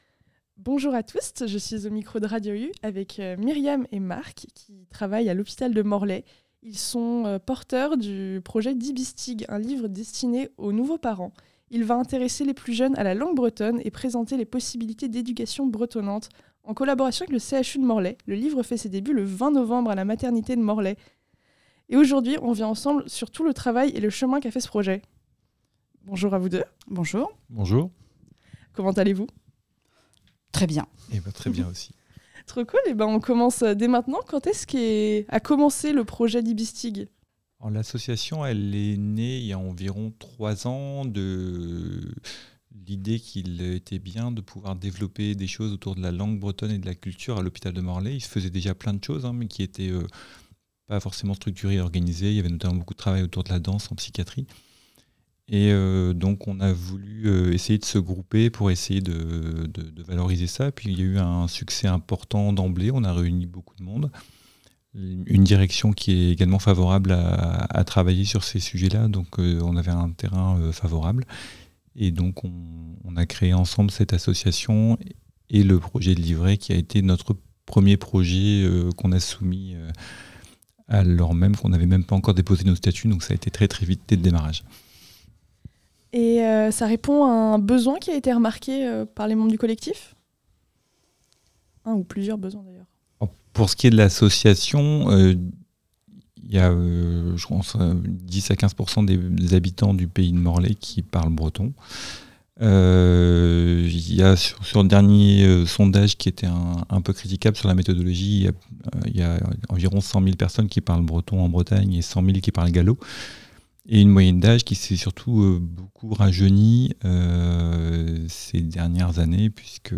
itw_dibistg_montee.mp3